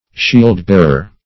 Search Result for " shield-bearer" : The Collaborative International Dictionary of English v.0.48: Shield-bearer \Shield"-bear`er\, n. 1.